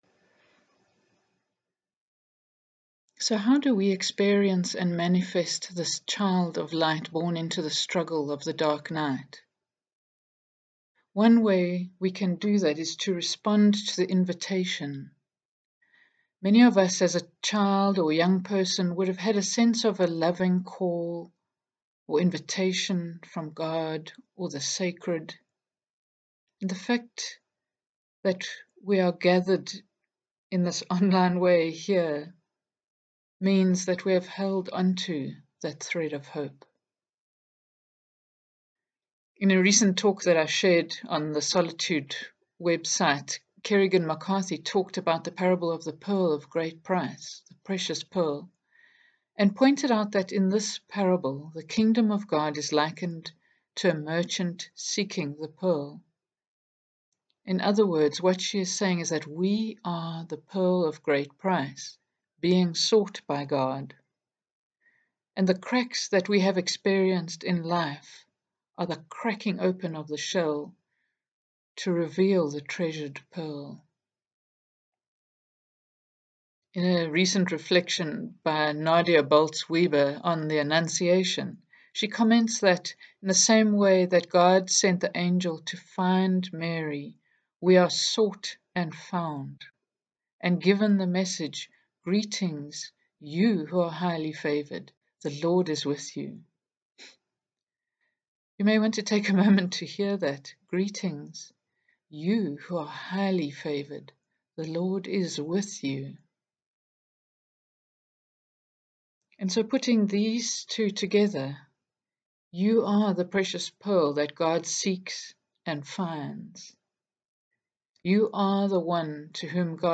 In this online Christmas reflection, we are going to interweave music, readings and reflections as we celebrate the coming of Christ this Christmas time.
Opening music: O come O come Immanuel (sung by Enya)